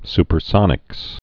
(spər-sŏnĭks)